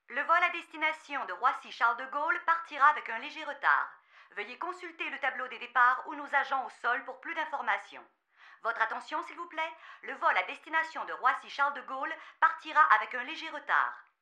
描述：机场播音员女性和男性，主要是法语（一些英语和西班牙语）。
标签： 公共地址 定位资产 机场 播音员
声道立体声